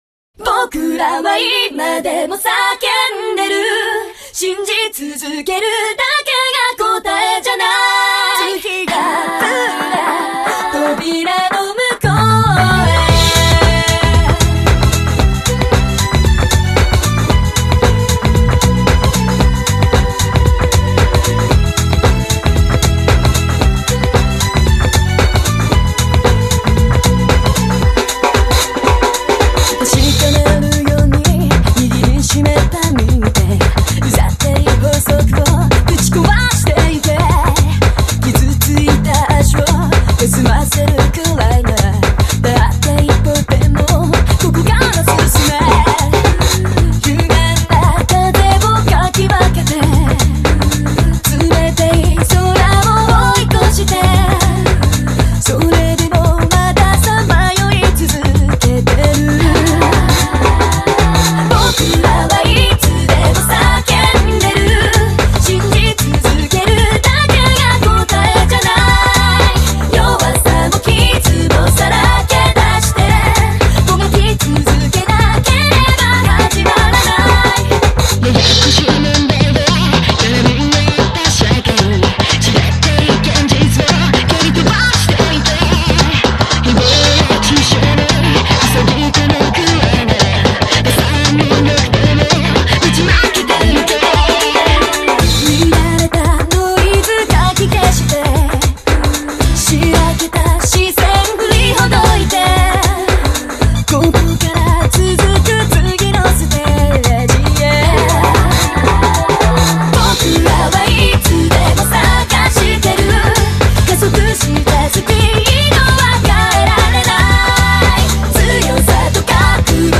remix de l'originale